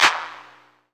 Index of /90_sSampleCDs/Best Service Dance Mega Drums/CLAPS HOU 1B